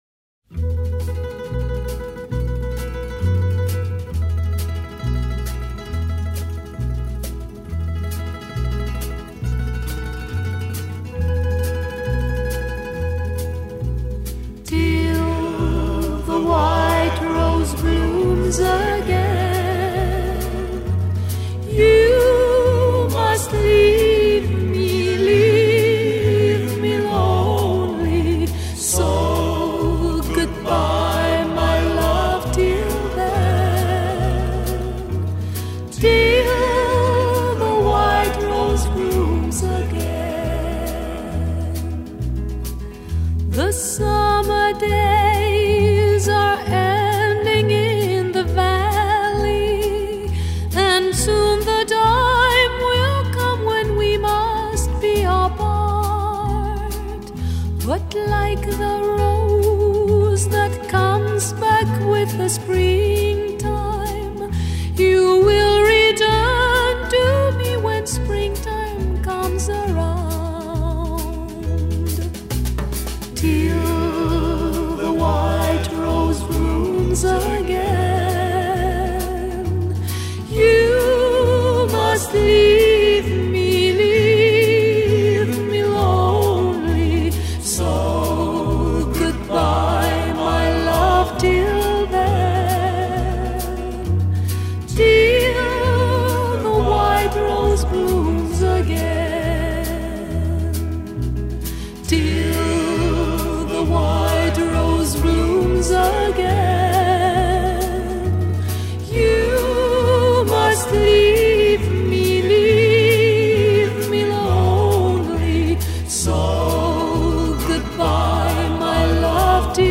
音乐类型：西洋音乐